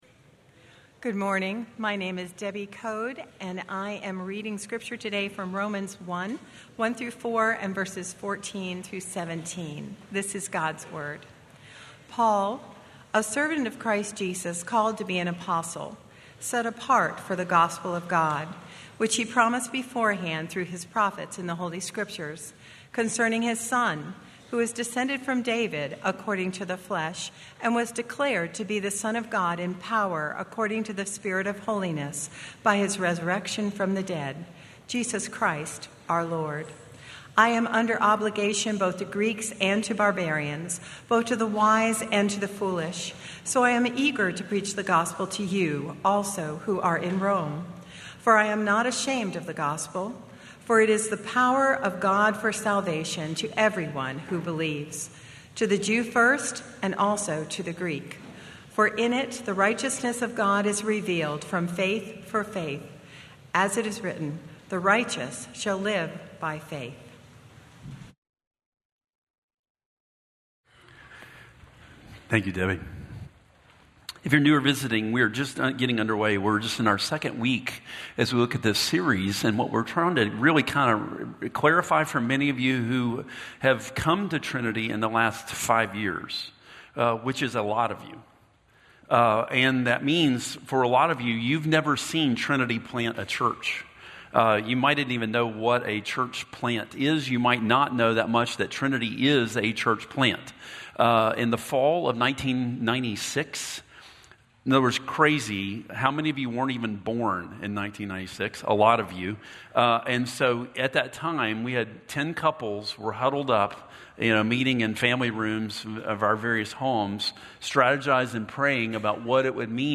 sermon-audio-6-8-25.mp3